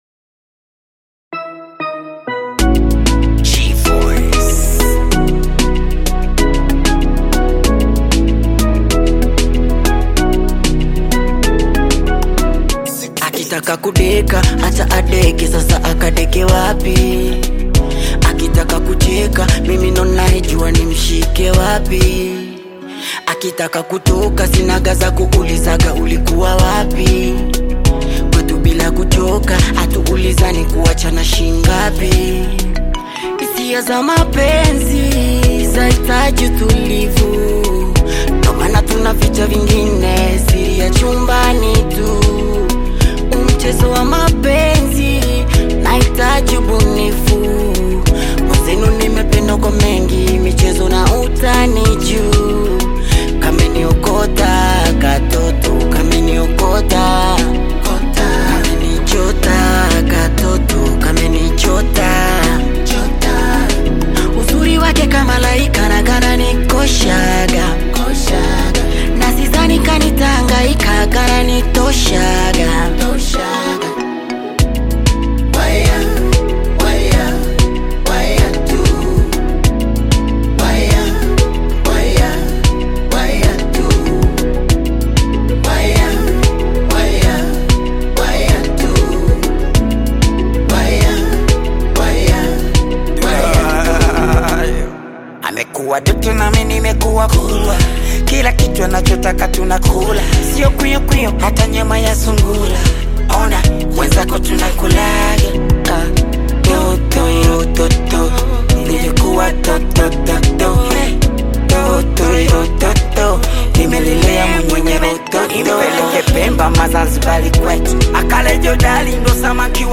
Upcoming bongo flava artist